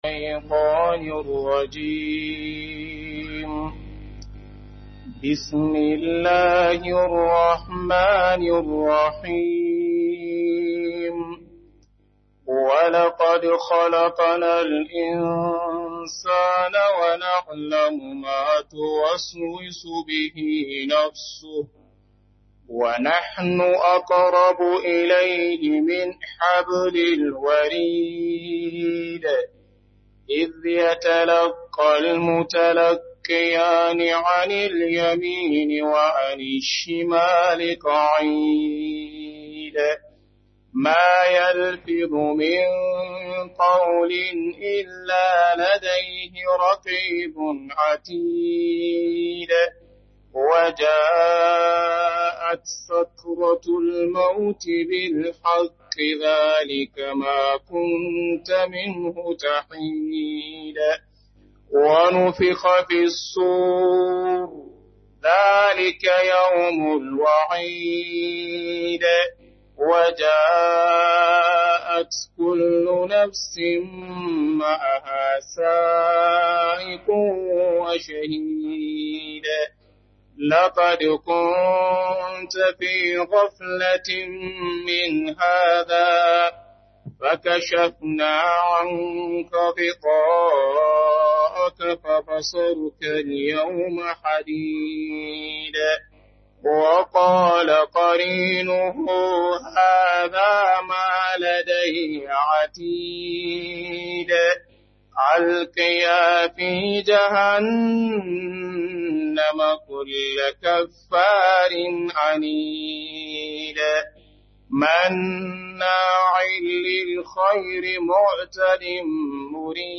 Kalubalen Makiya Ina Mafita - Muhadara